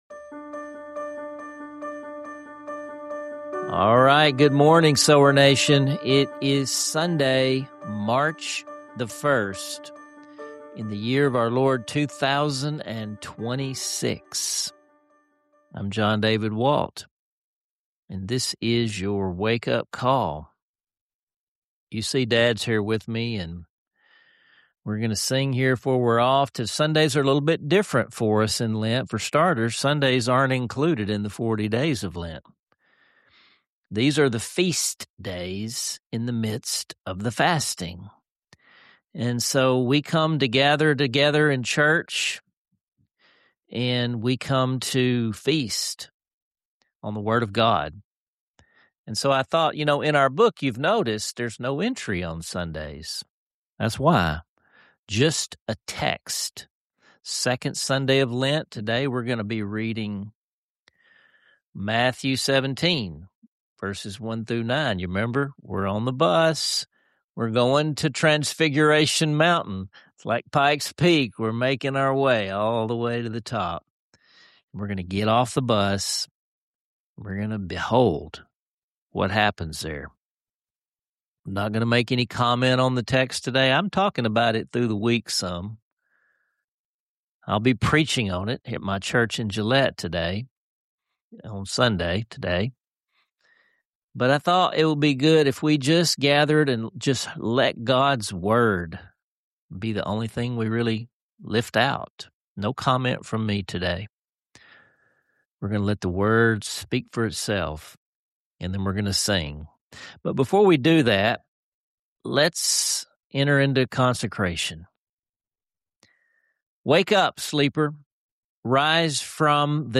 Together, they lead the classic hymn “My Jesus I Love Thee,” sharing not just music but a loving family dynamic that will warm your heart.
Singing “My Jesus, I Love Thee” Together